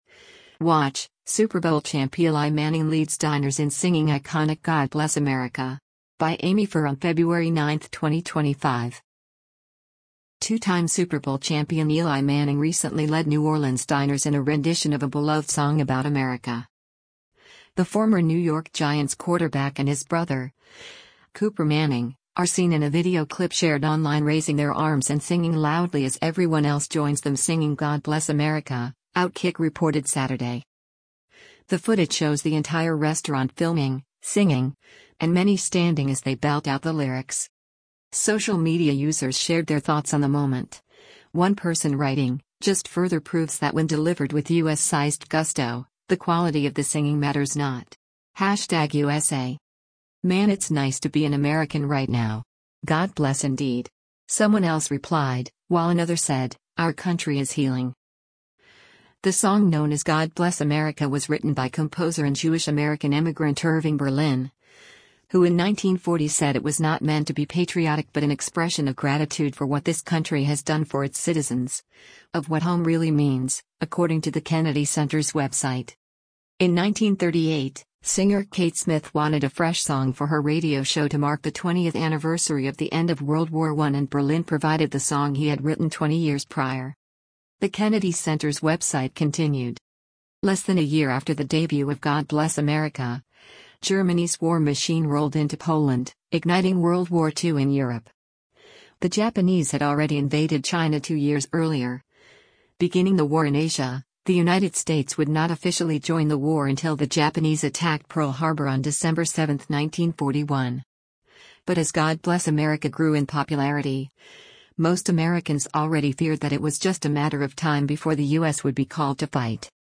The footage shows the entire restaurant filming, singing, and many standing as they belt out the lyrics: